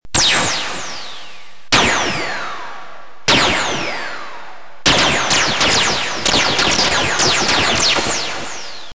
Photon shot with ricochets
Category: Sound FX   Right: Personal
Tags: Photon Sounds Photon Sound Photon clips Sci-fi Sound effects